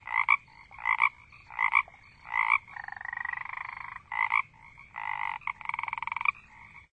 frog_3.ogg